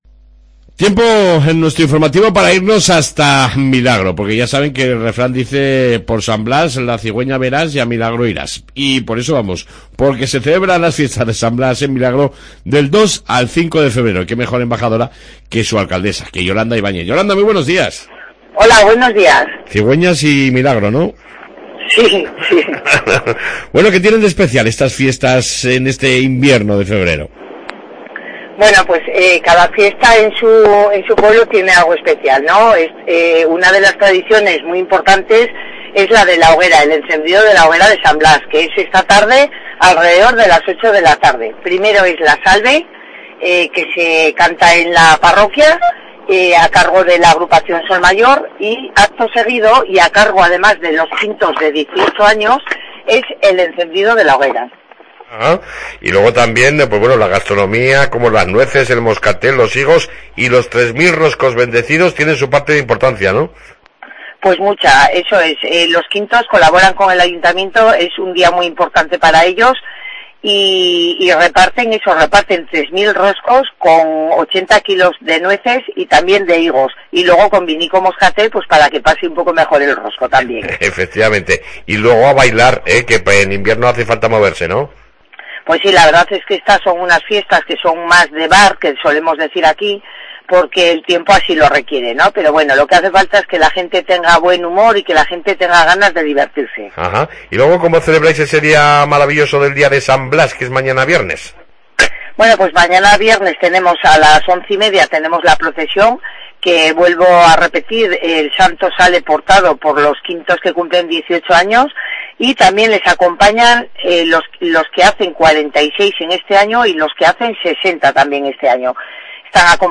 AUDIO: Entrevista con la alcaldesa de Milagro, Yolanda Ibañez, sobre las Fiestas de san Blas que comienzan hoy en Milagro.